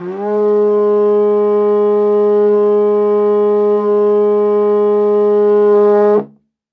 Index of /90_sSampleCDs/Best Service ProSamples vol.52 - World Instruments 2 [AKAI] 1CD/Partition C/TENOR HORN